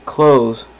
Close Sound Effect
close-2.mp3